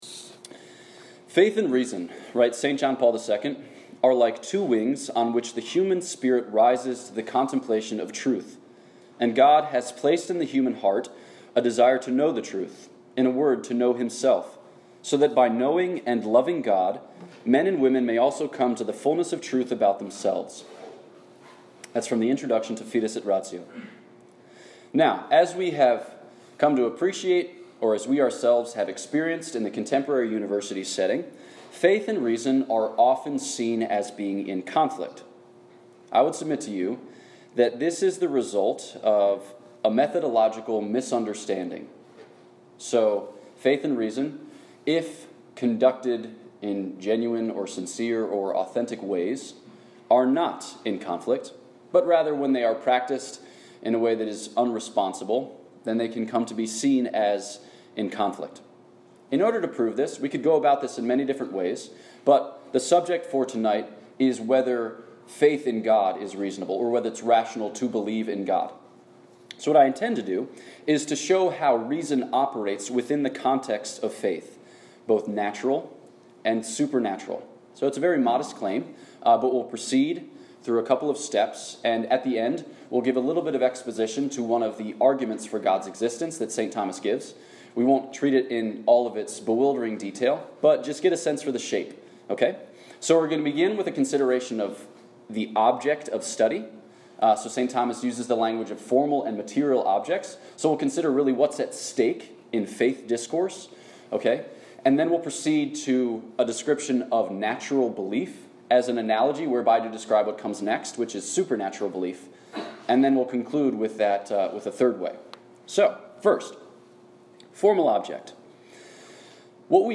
This lecture was given at the University of Oklahoma on 23 October 2019.